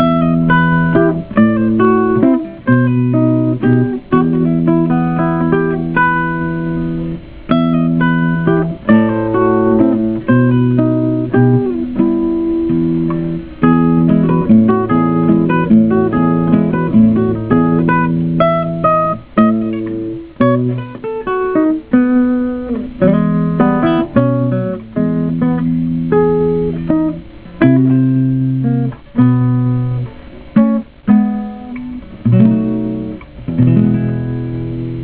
Tuning: EADGBE Key:G major Sample: